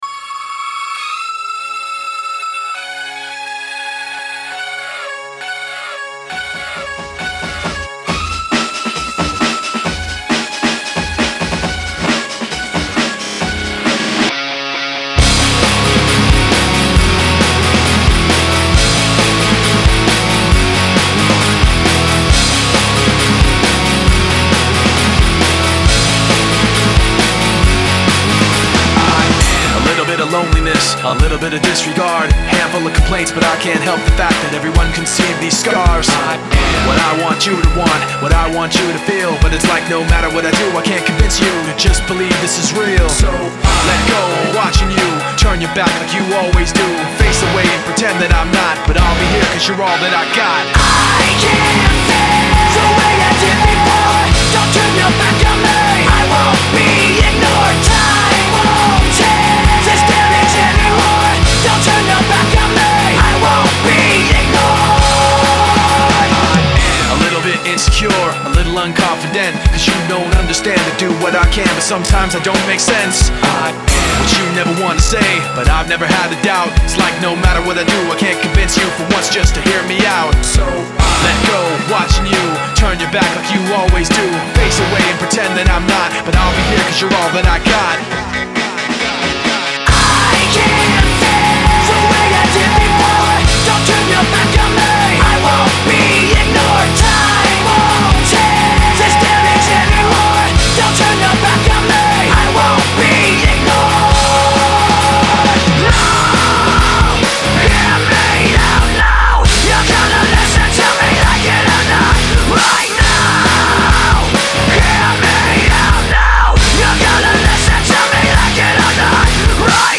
Genre : Rock, Alternative Rock